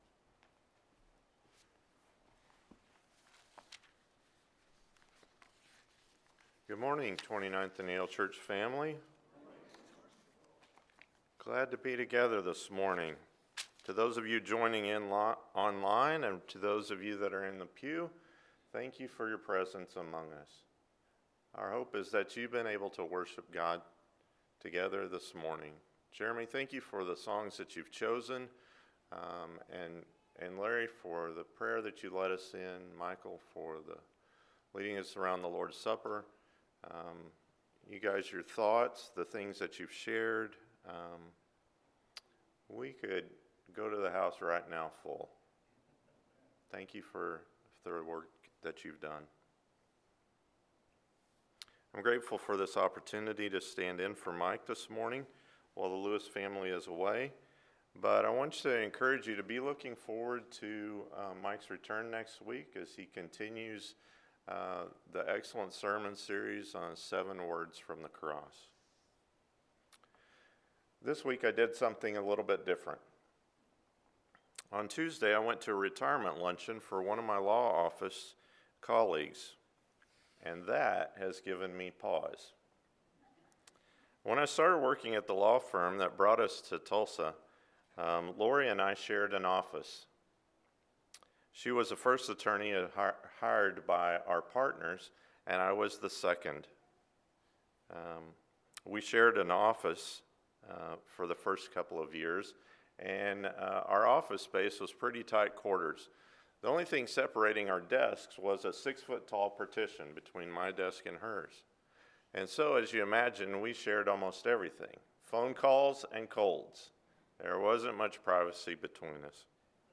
Because of God – I Corinthians 1:30-31 – Sermon — Midtown Church of Christ